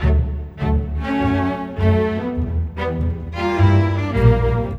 Rock-Pop 10 Strings 01.wav